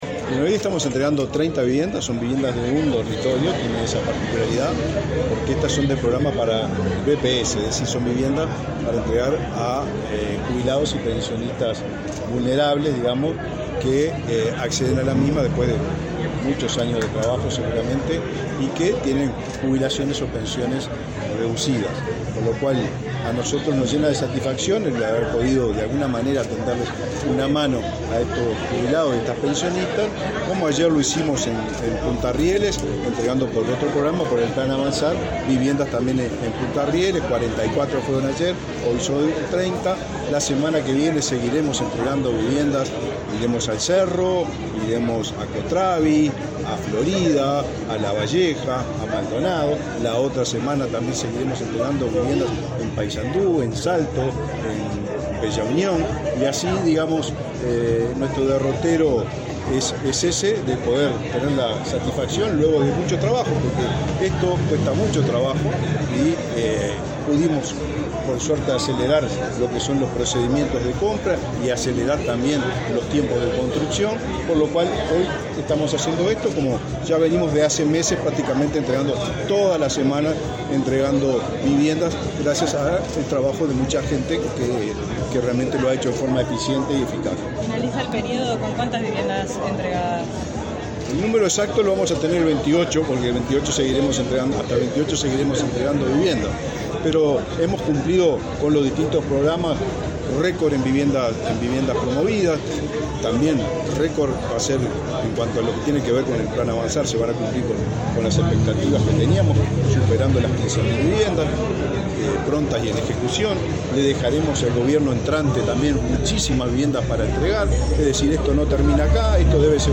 Declaraciones a la prensa del ministro de Vivienda y Ordenamiento Territorial, Raúl Lozano
Declaraciones a la prensa del ministro de Vivienda y Ordenamiento Territorial, Raúl Lozano 14/02/2025 Compartir Facebook X Copiar enlace WhatsApp LinkedIn El titular del Ministerio de Vivienda y Ordenamiento Territorial, Raúl Lozano, participó, este 14 de febrero, en la entrega de 30 viviendas para jubilados y pensionistas del Banco de Previsión Social, construidas por la citada cartera. Tras el evento, Lozano realizó declaraciones a la prensa.